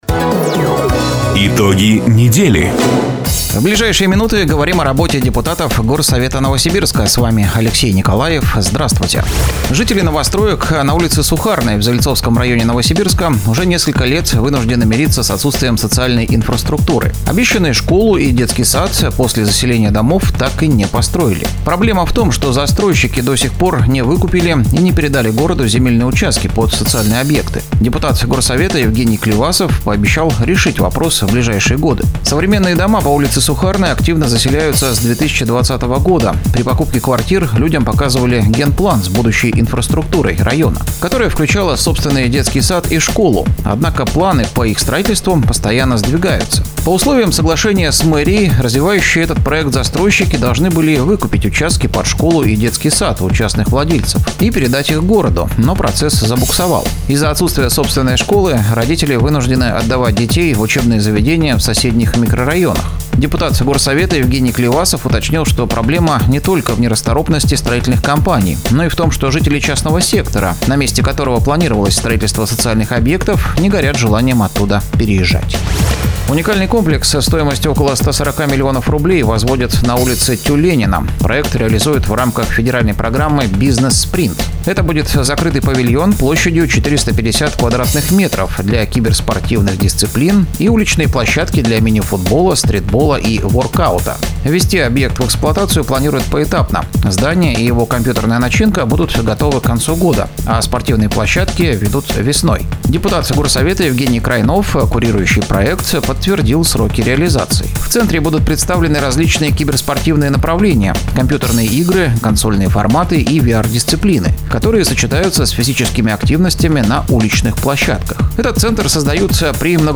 Запись программы "Итоги недели", транслированной радио "Дача" 01 ноября 2025 года